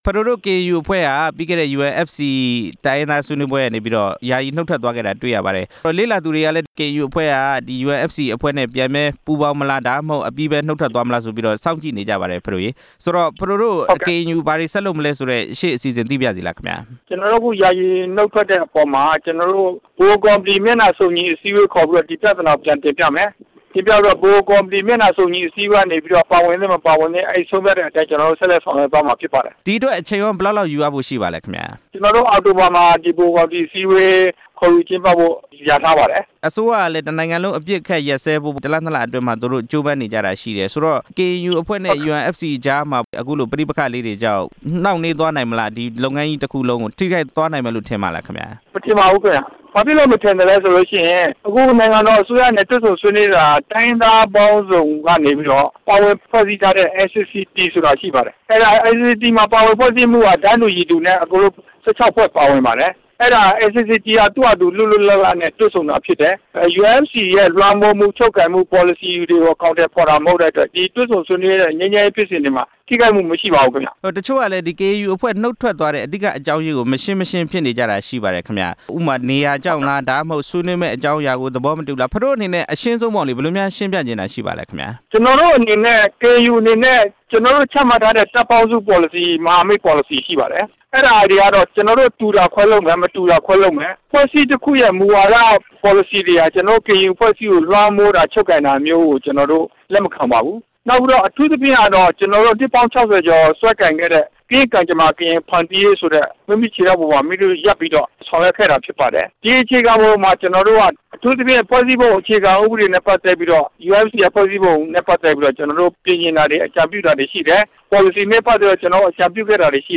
UNFC မှာ KNU ပြန်လည်ပူးပေါင်းရေး အလားအလာ မေးမြန်းချက်